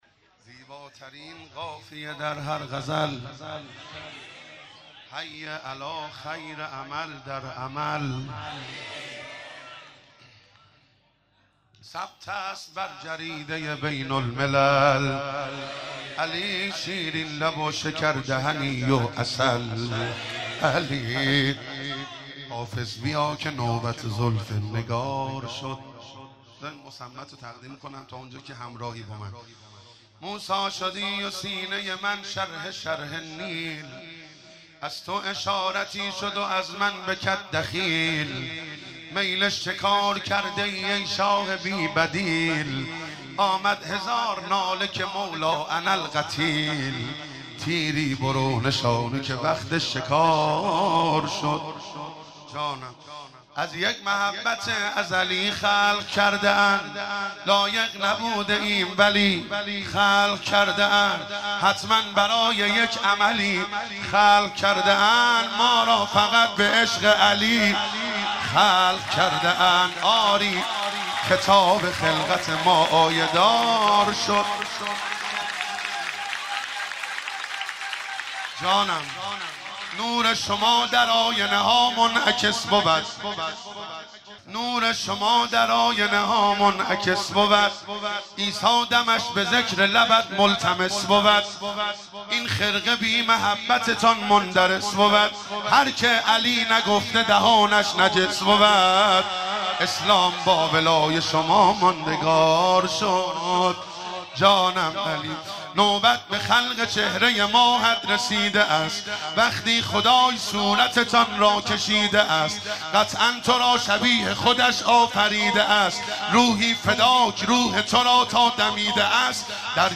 گلچین مولودی مبعث پیامبر